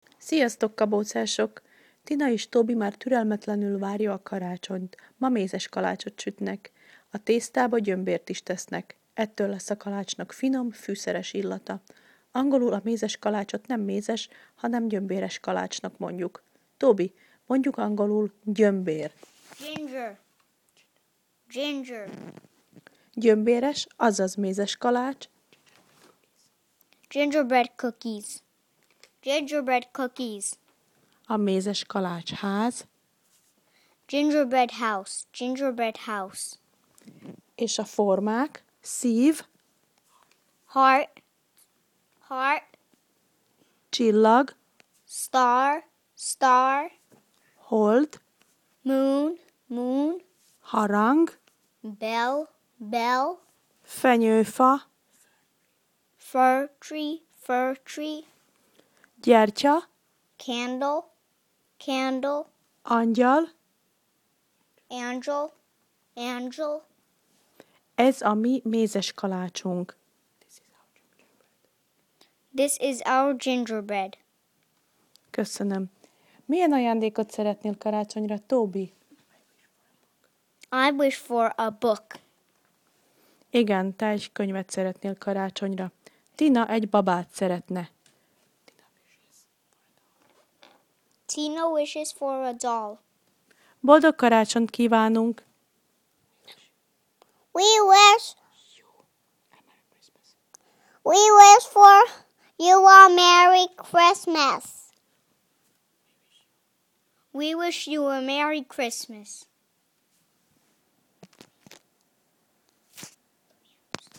Az ebben a leckében szereplő szavak helyes kiejtését meghallgathatod Tobytól.